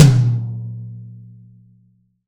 Index of /90_sSampleCDs/Best Service - Real Mega Drums VOL-1/Partition D/AMB KIT 02EC